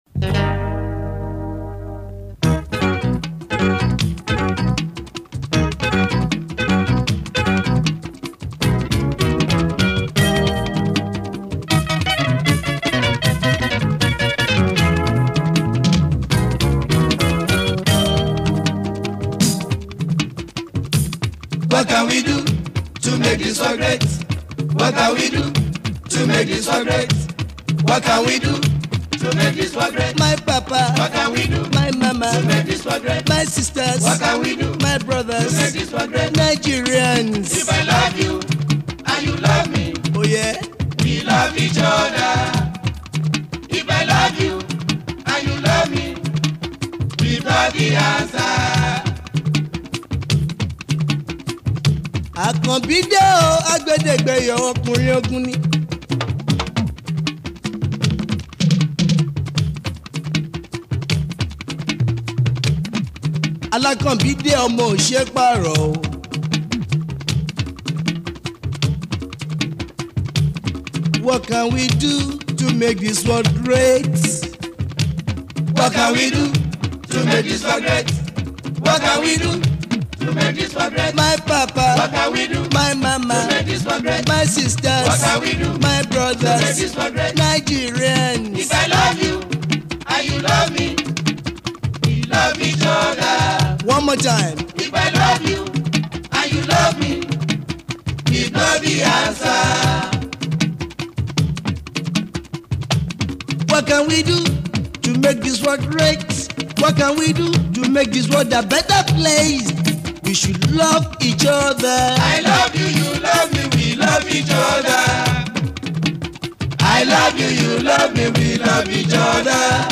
is a Nigerian Jùjú musician.